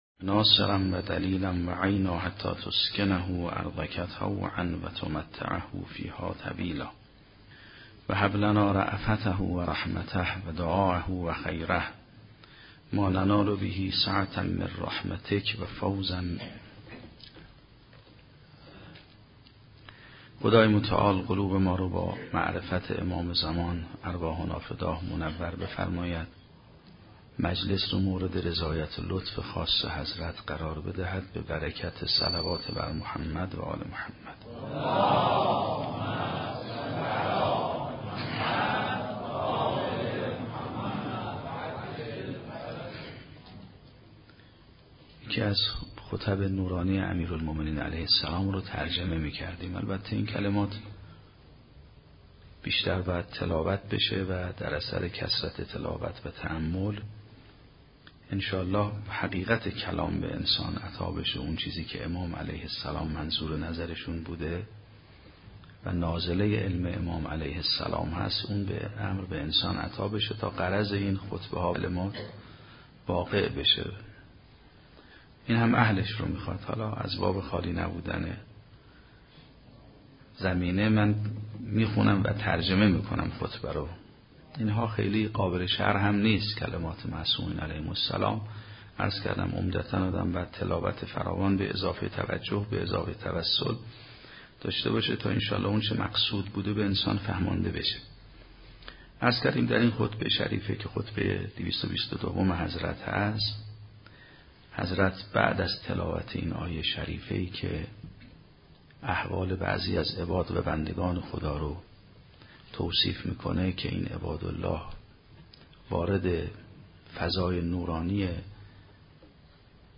جلسه دوم از سخنرانی آیت الله سیدمحمدمهدی میرباقری در دهه اول محرم 96
در حسینیه حضرت زهراء سلام الله علیها